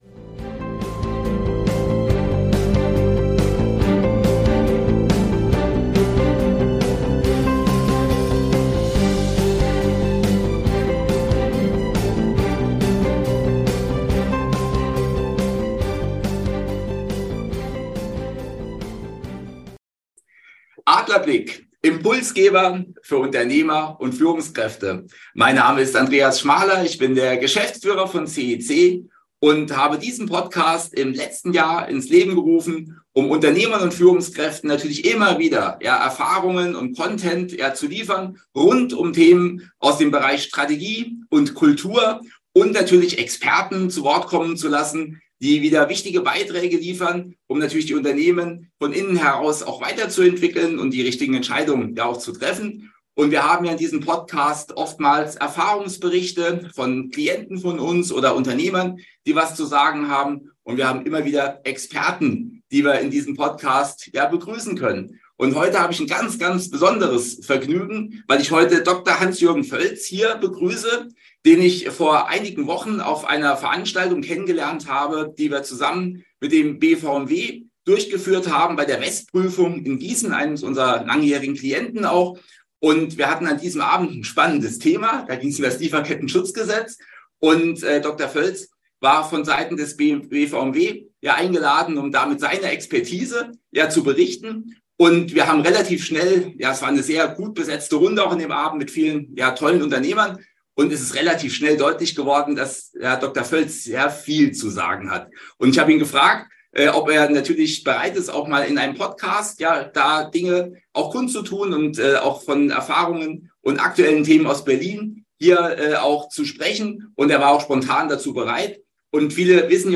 Beschreibung vor 3 Jahren Wir freuen uns, heute einen ganz besonderen Gast bei uns begrüßen zu dürfen.